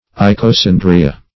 Search Result for " icosandria" : The Collaborative International Dictionary of English v.0.48: Icosandria \I`co*san"dri*a\, n. pl.